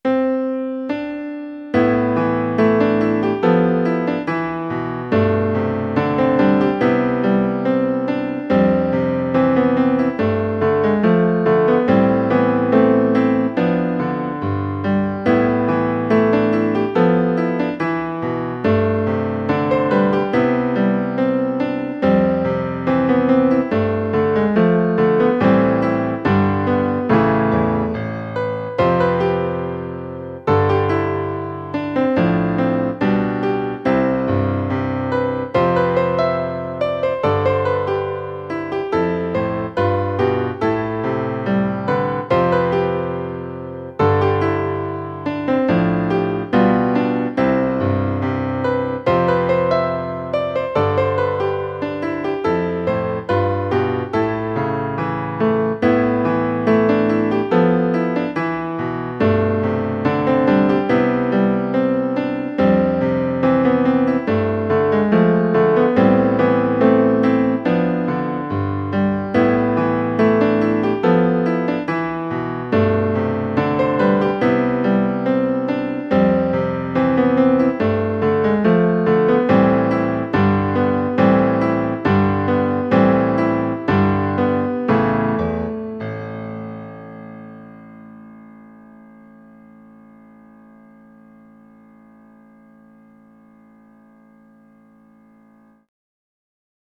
例えば、「Aセクションのメロディラインが跳ねるようなフレーズなら、逆にBセクションのラインは流れるようなフレーズにする。」等です。
ジブリ音楽の構造を取り入れて曲を作ってみました。
作例では、特徴②と④を使用して作っています。
「Aセクション→Bセクション→Aセクション→コーダ」の順番で作っています。